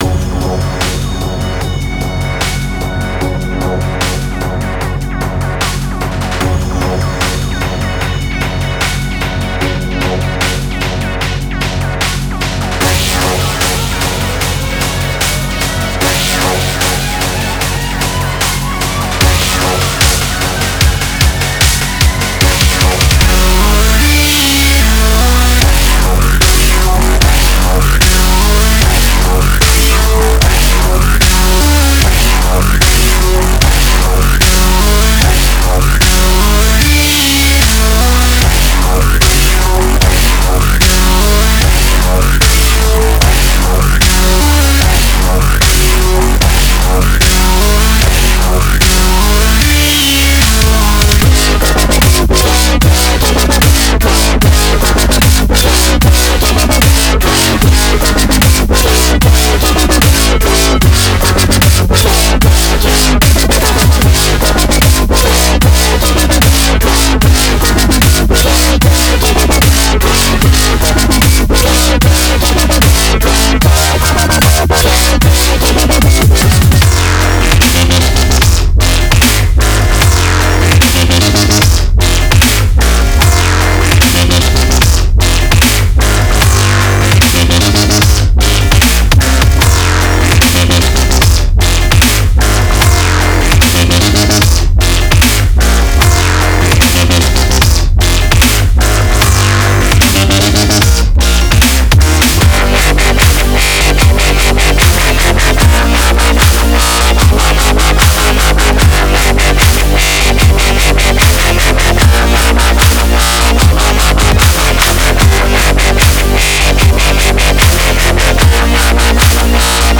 デモサウンドはコチラ↓
Genre:Dubstep
52 Synth Loops
32 Bass Loops